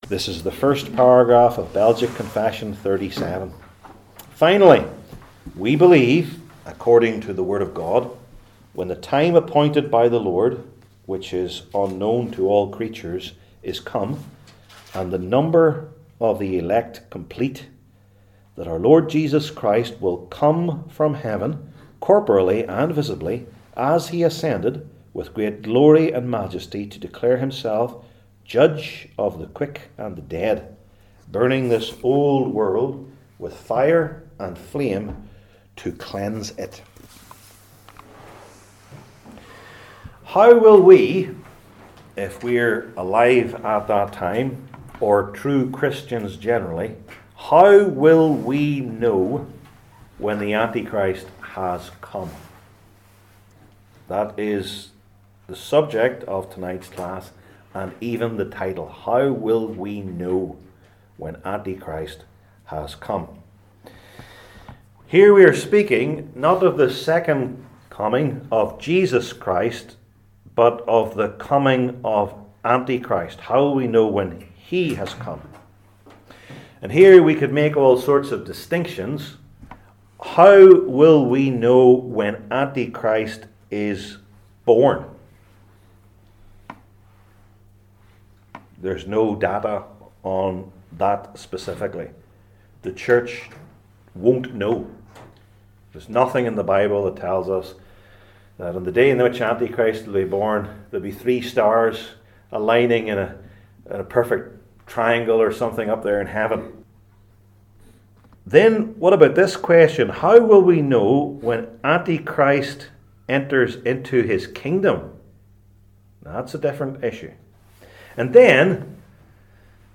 Passage: II Thessalonians 2:1-12 Service Type: Belgic Confession Classes